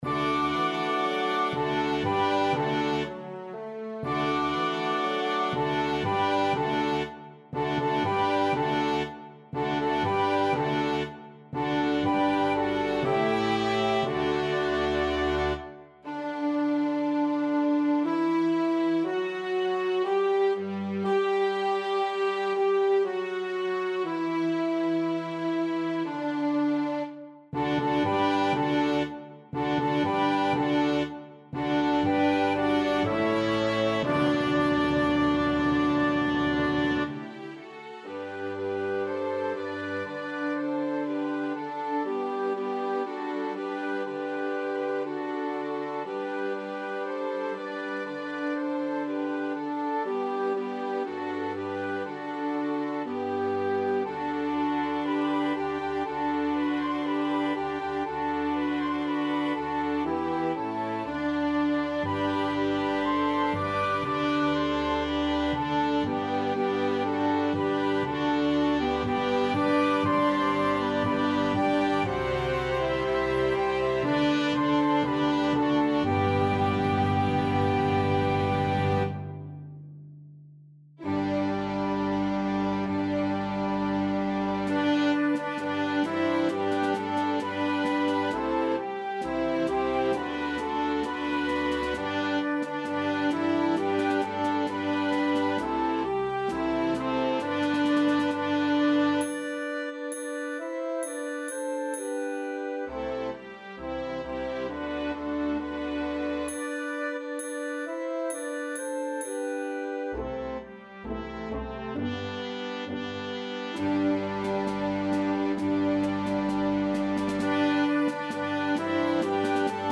Strings (Violin 1, Violin 2, Violin 3, Viola, Cello, Bass)
Optional Piano.